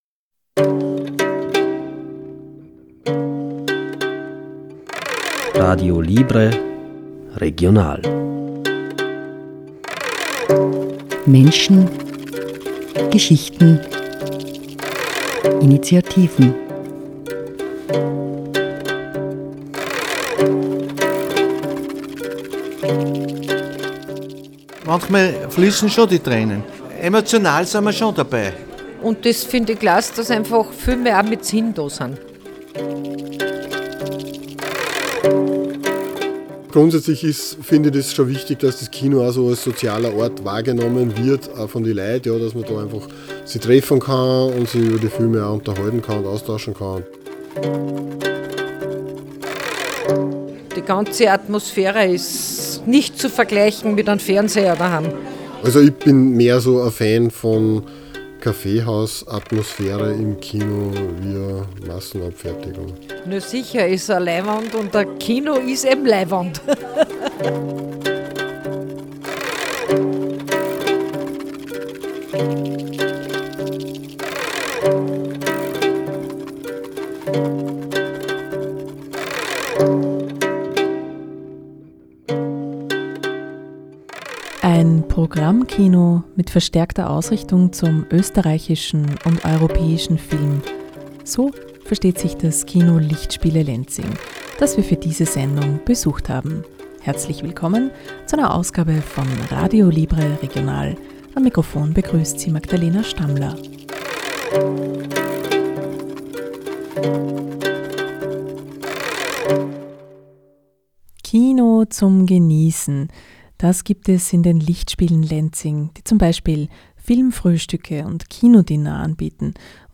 Als Programmkino mit verstärkter Ausrichtung zum österreichischen und europäischen Film versteht sich das Kino Lichtspiele Lenzing, das wir für diese Sendung besucht haben.